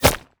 bullet_impact_rock_08.wav